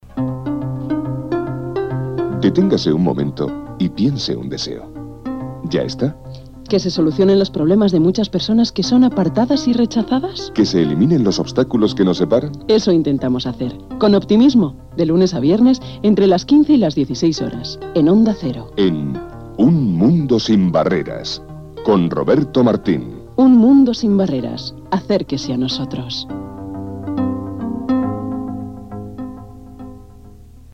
Promoció del programa "Un mundo sin barreras"